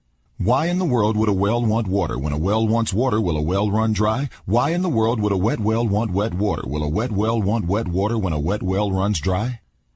tongue_twister_04_01.mp3